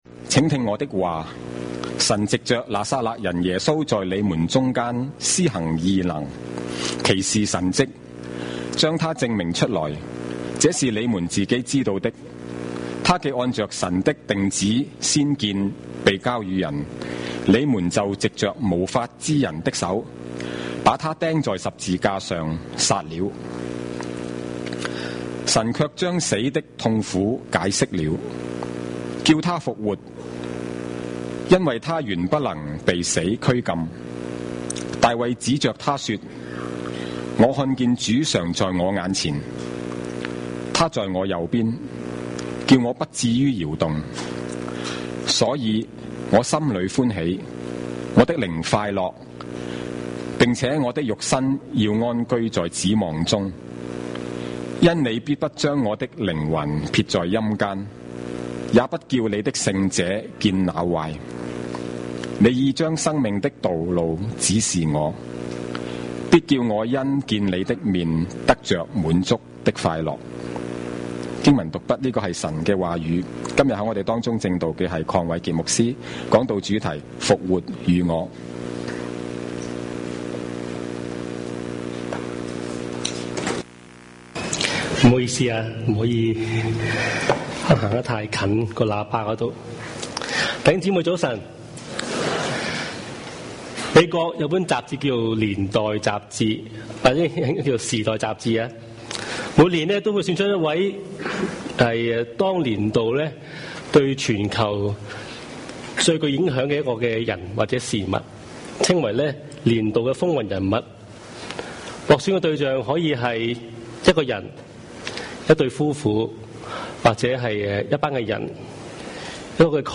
華埠粵語二堂 標籤